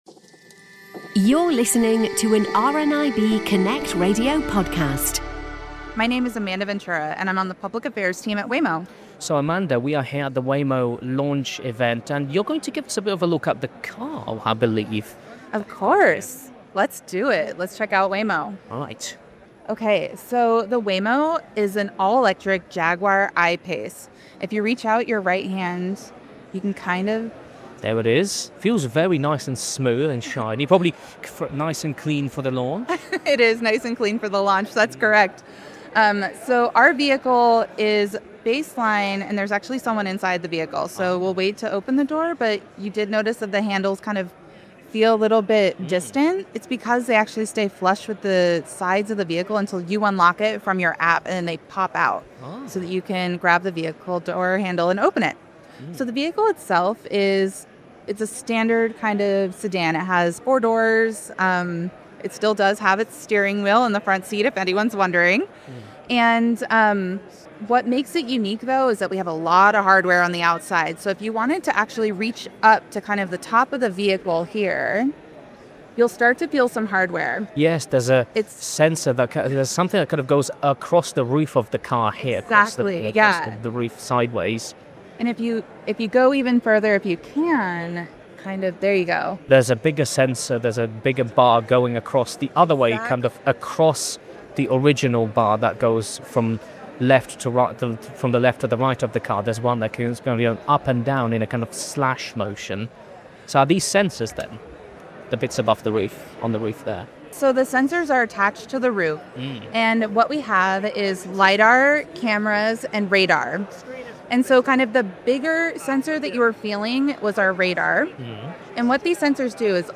Waymo Launch Event - Walking Around The Car